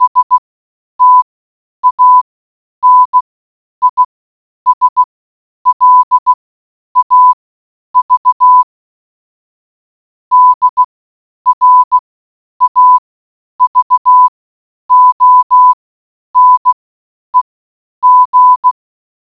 Morse code. Loop. 00:19.
morsecode.wav